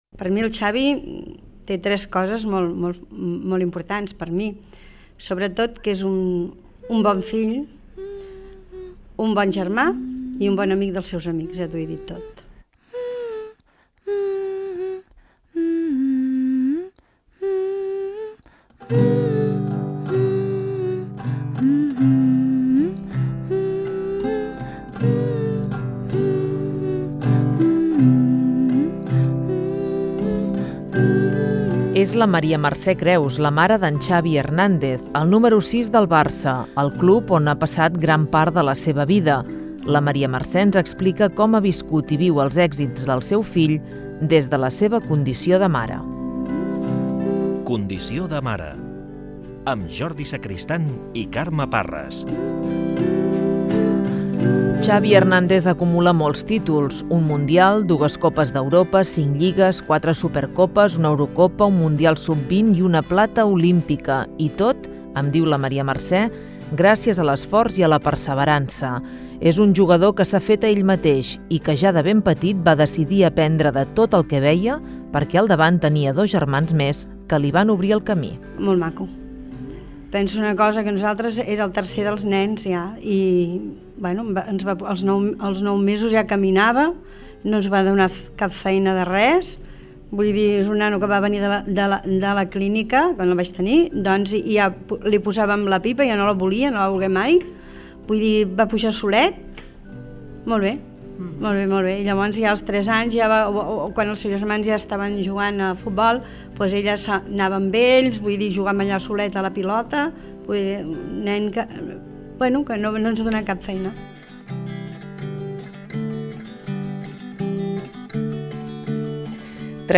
Fragment extret de l'arxiu sonor de COM Ràdio.